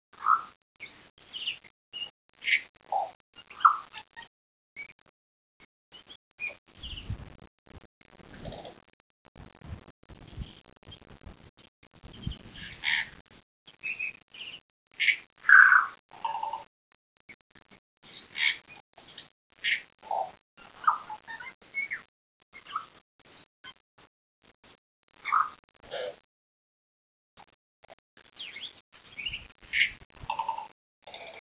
After a quick dinner of leftover curry, bread and cheese we drove out to Otari-Wilton's Bush, an incredible park/botanic garden.
Tui birds filled the air with their bizzare clicks, clacks, warbles and song.